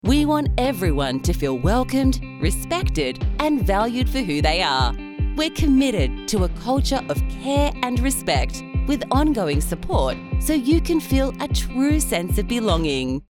Female
English (Australian)
Adult (30-50)
Corporate
Internal Corporate Video
Words that describe my voice are Humorous, Energetic, Professional.